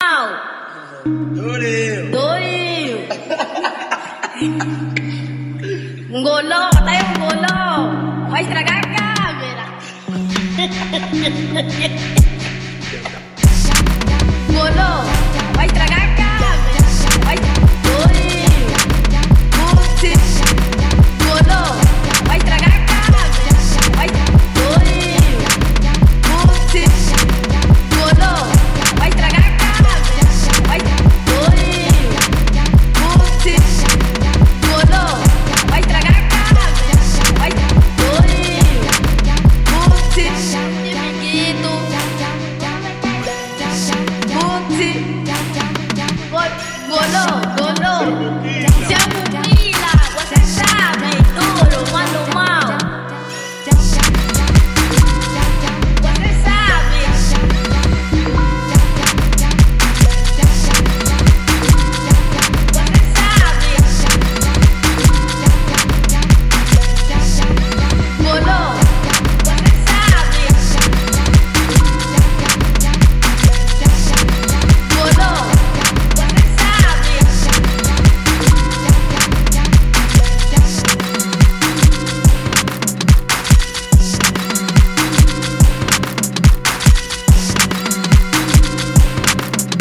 no meio de um disco mais geralmente soturno no som